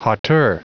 Prononciation du mot hauteur en anglais (fichier audio)